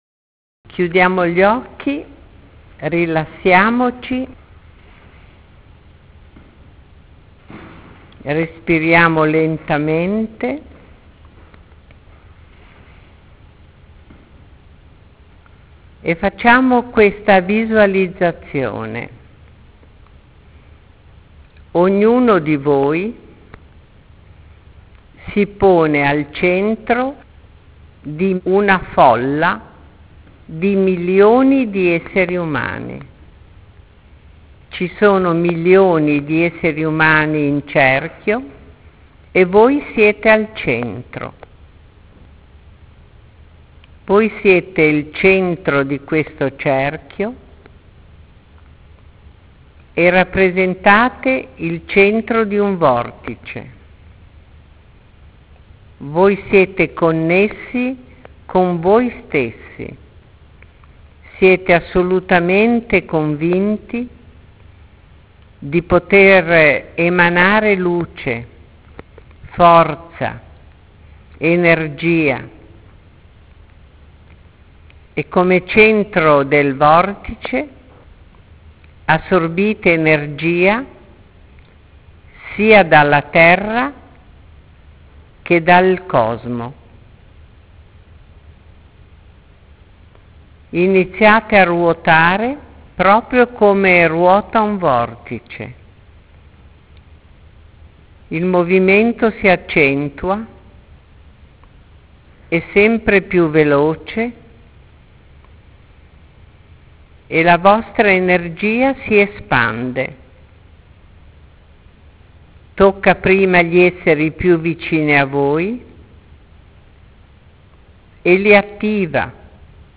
Meditazione – Vortici